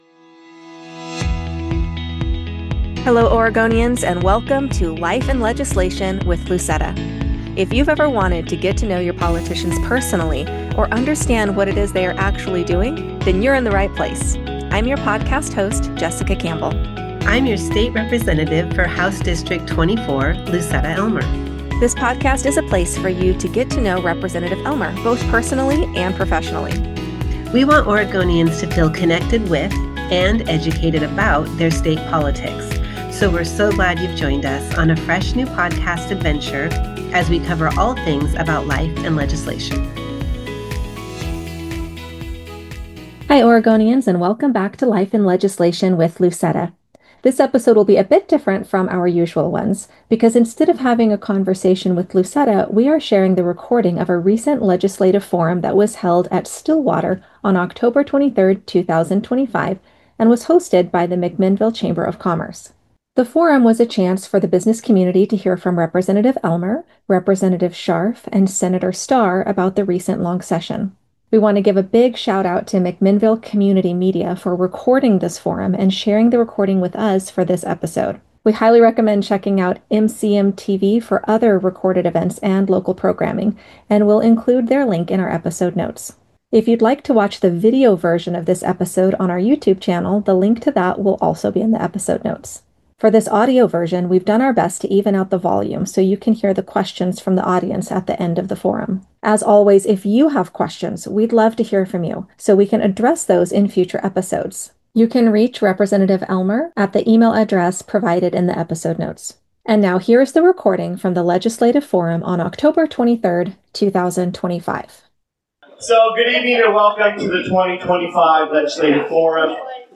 In this episode, we share the recording of a legislative forum, hosted by the McMinnville Chamber of Commerce, on October 23rd, 2025, at Stillwater.